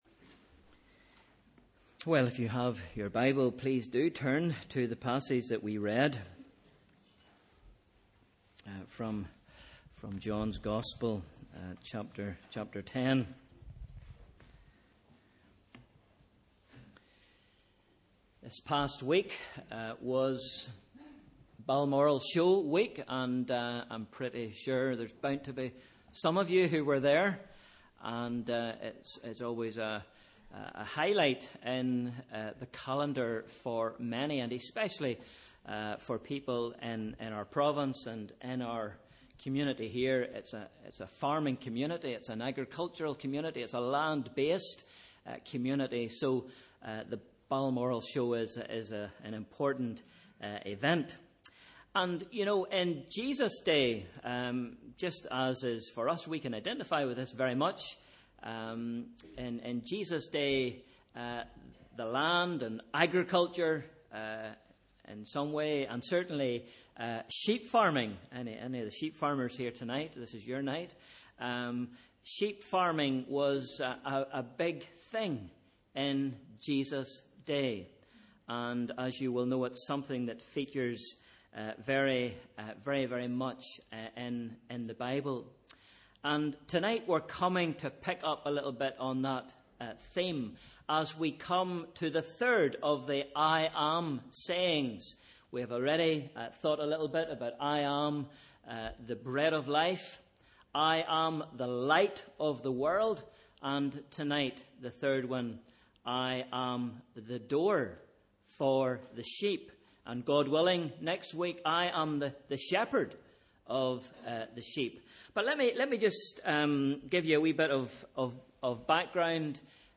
Sunday 15th May – Morning Service